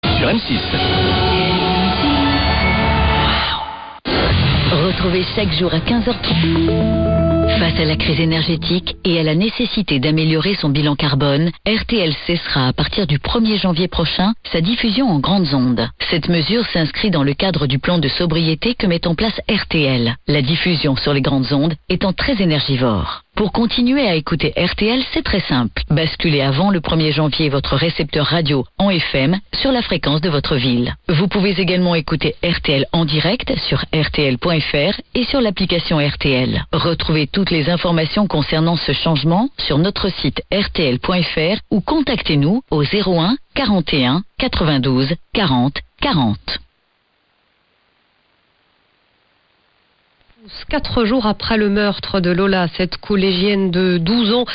Bande annonce de RTL / promo spot closure